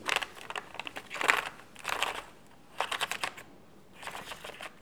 bruit-page_03.wav